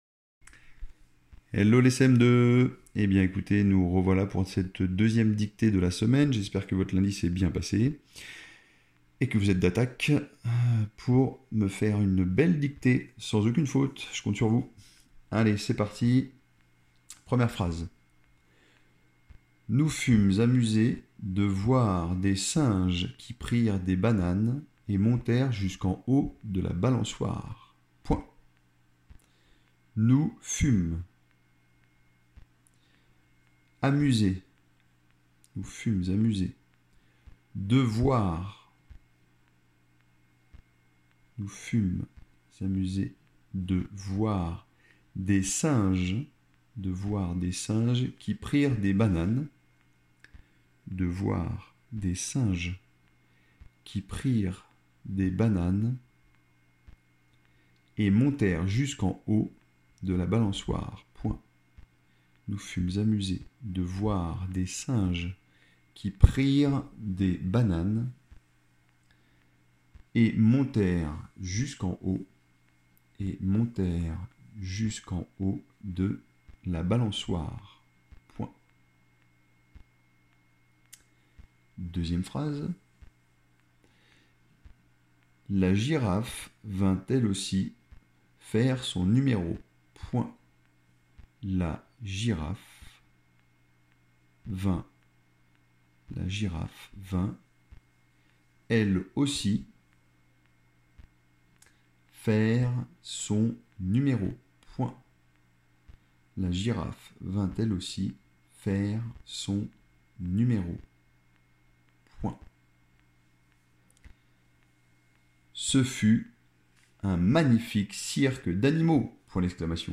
-Dictée :